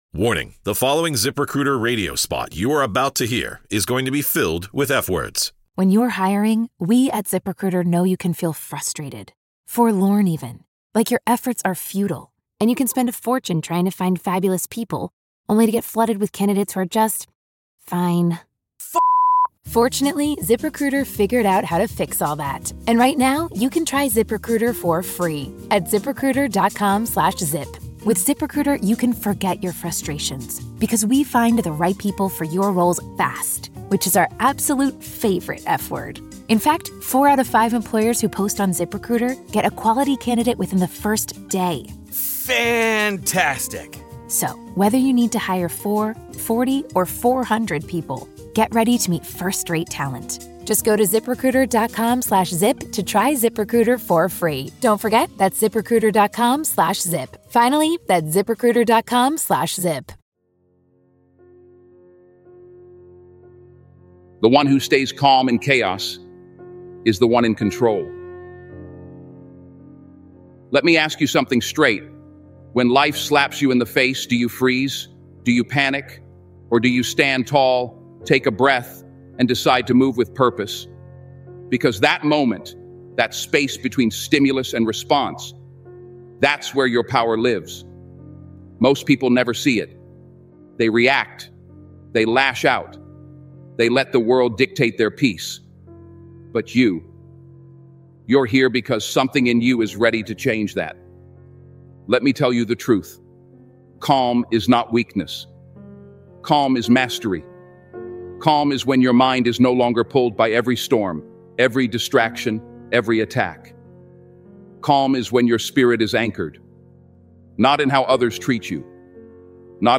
Denzel Washington - Train your mind to stay calm not matter what motivational speech